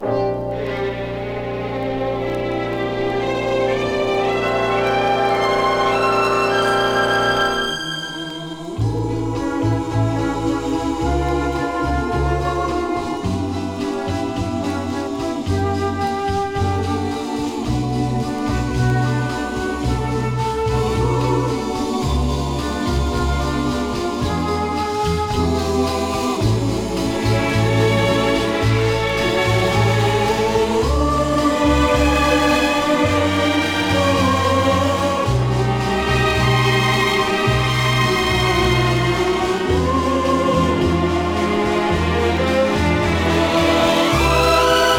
軽やかで流麗な演奏が最高で、素敵な瞬間がただただ溢れ出す好盤です。
Jazz, Pop, Easy Listening　USA　12inchレコード　33rpm　Stereo